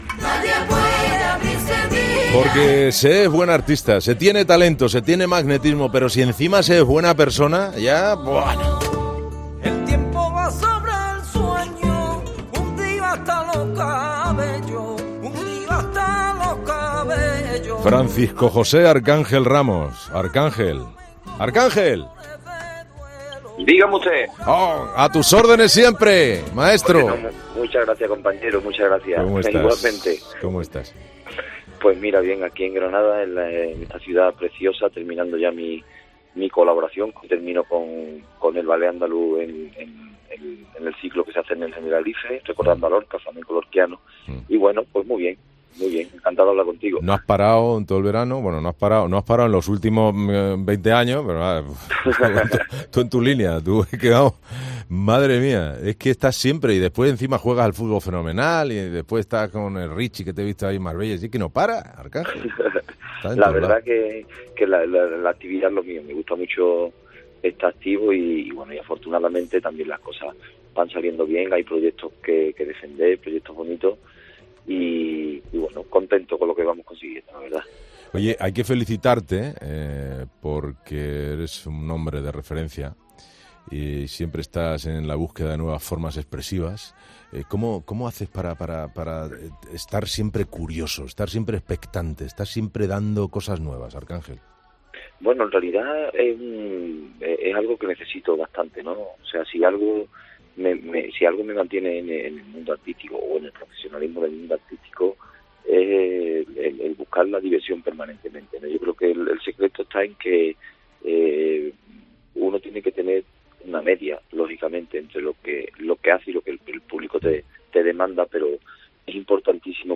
Entrevista al cantaor flamenco Arcdángel